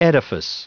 Prononciation du mot edifice en anglais (fichier audio)